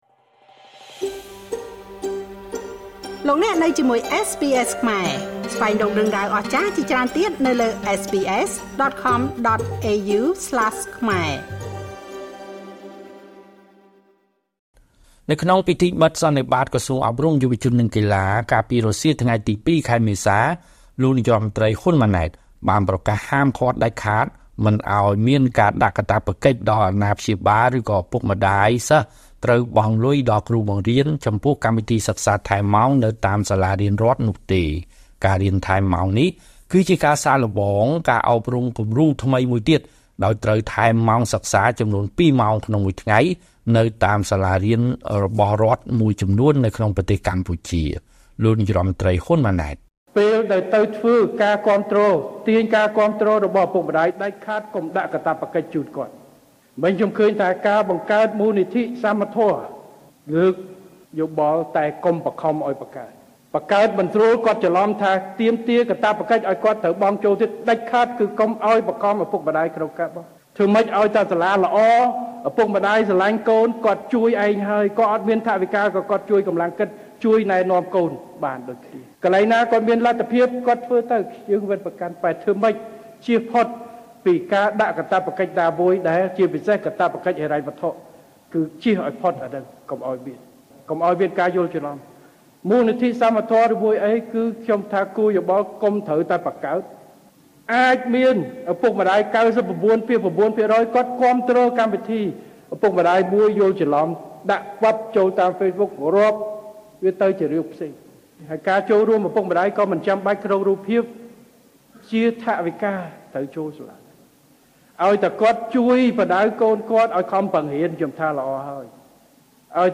សំឡេងលោកនាយករដ្ឋមន្ត្រី ហ៊ុន ម៉ាណែត៖ ( សំឡេង )